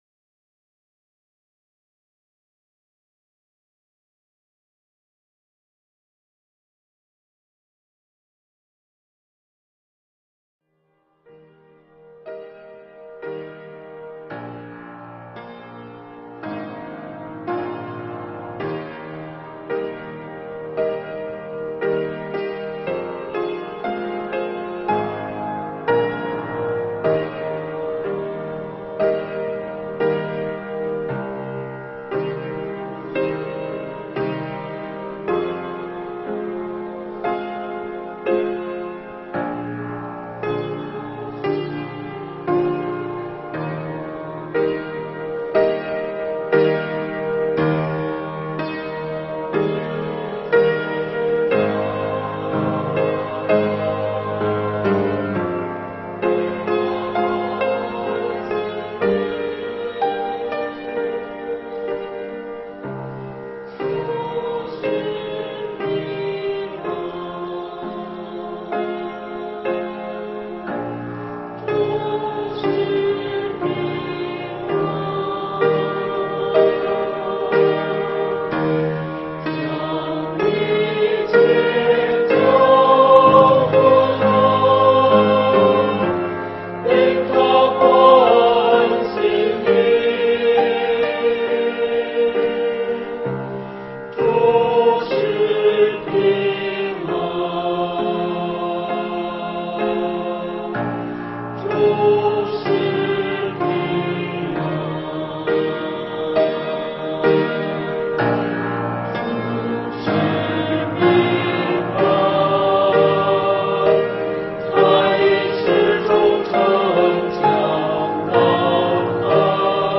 证道内容： 列王纪上第20章有一句核心句，一共出现了两次：“今日我必将他们交在你手里，你就知道我是耶和华。”（王上20:13、28）